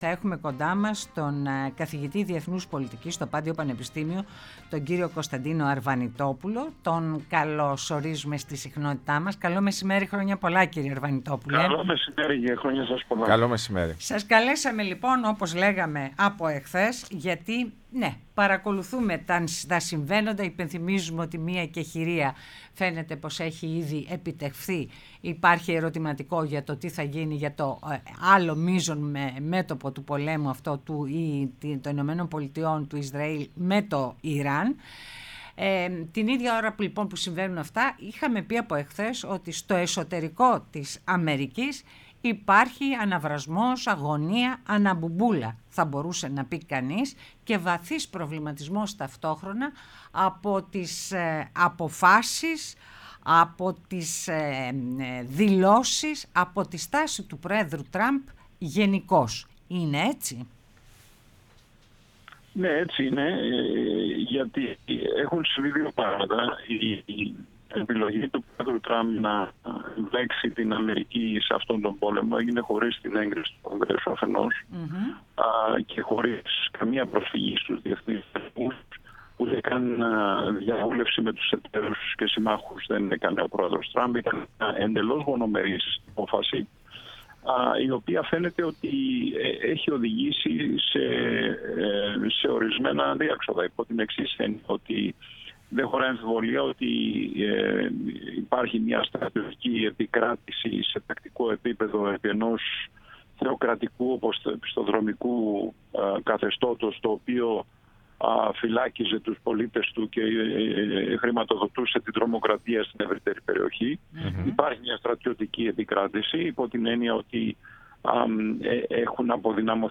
Ο Κωνσταντίνος Αρβανιτόπουλος, καθηγητής Διεθνούς Πολιτικής στο Πάντειο Πανεπιστήμιο, μίλησε στην εκπομπή «Ναι, μεν Αλλά»